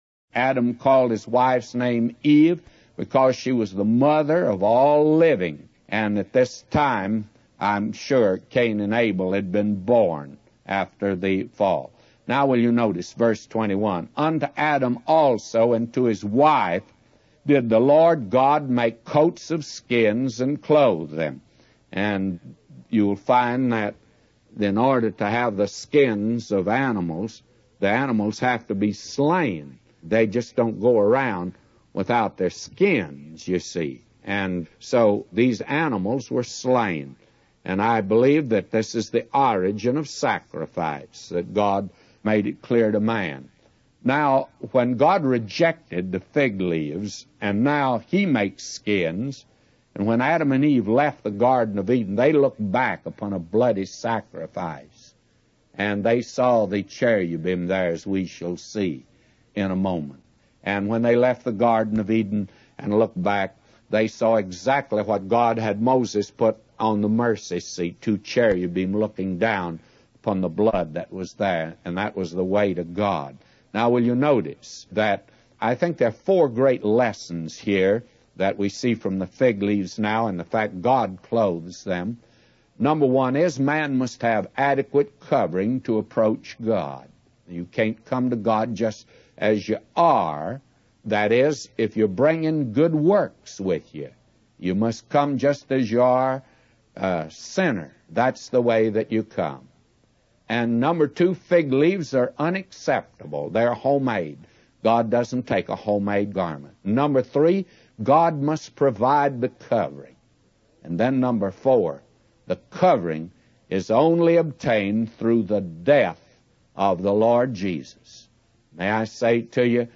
In this sermon, the preacher discusses the concept of covering in relation to man's relationship with God. He emphasizes that man must have an adequate covering to approach God and that fig leaves, representing man's own efforts, are unacceptable.